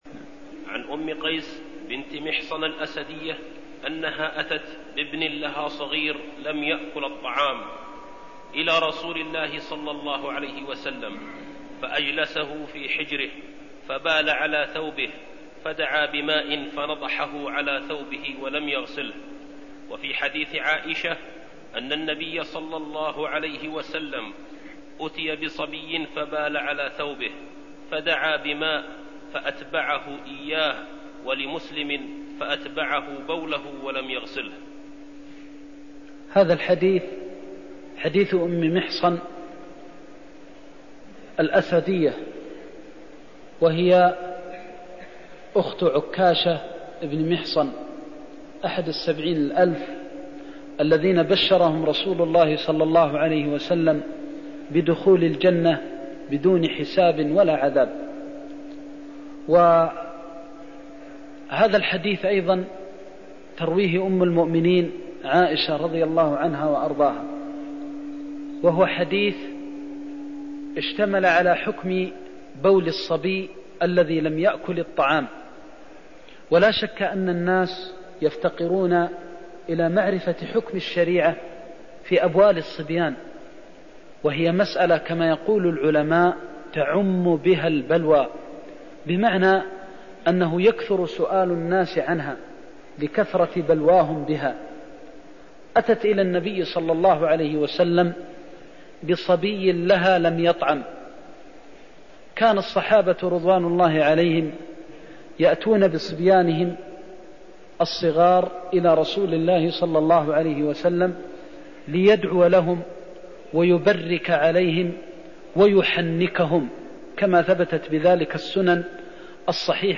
المكان: المسجد النبوي الشيخ: فضيلة الشيخ د. محمد بن محمد المختار فضيلة الشيخ د. محمد بن محمد المختار حكم الطهارة من بول الصبي (26)